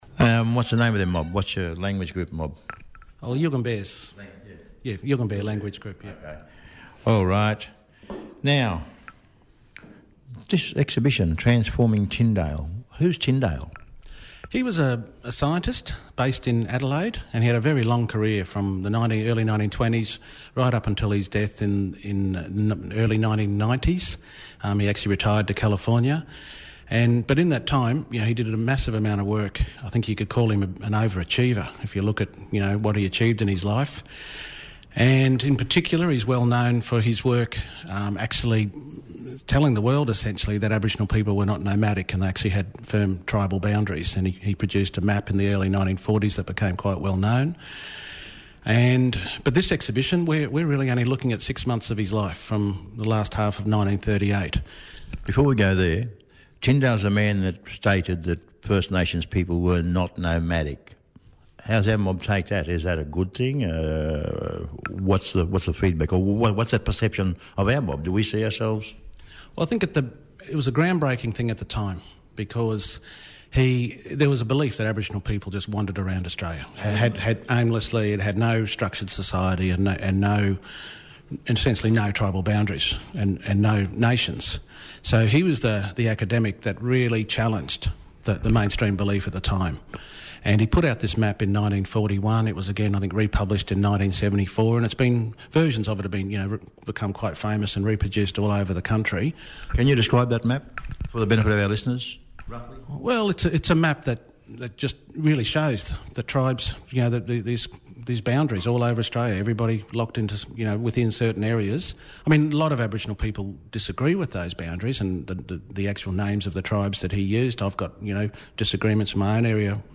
Live from the State Library of Queensland